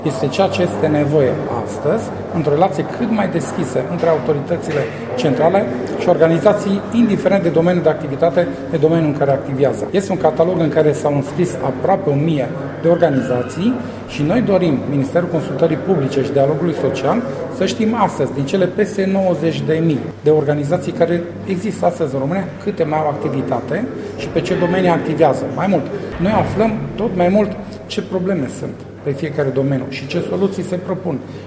Secretarul de stat în Ministerul Consultării Publice şi Dialogului Social, Vicenţiu Mircea Irimie a arătat la Tîrgu-Mureș că, din cele peste 90.000 de organizaţii neguvernamentale din România, câteva sute s-au înscris în catalog:
Subiectul a fost ridicat în cadrul Conferinţei Naţionale a Asociaţiilor de Proprietari de la Târgu Mureş.